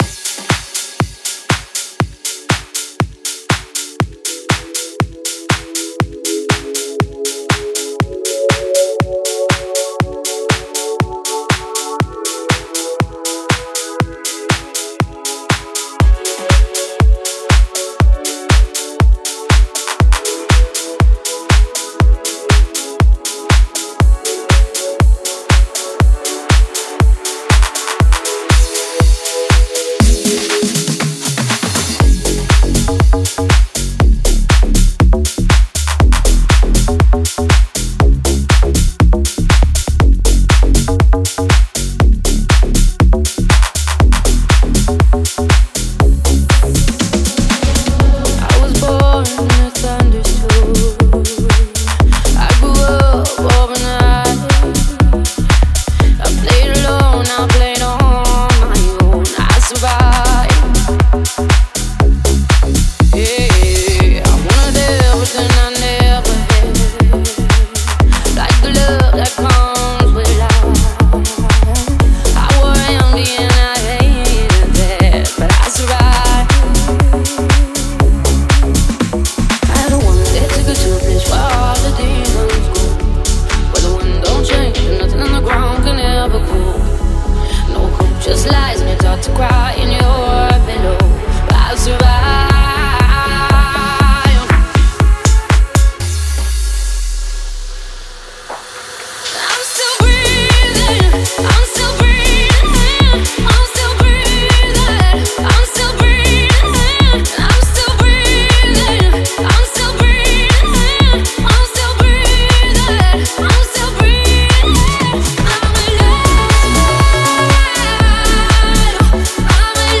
Стиль: Dance / Pop / Club House / Vocal House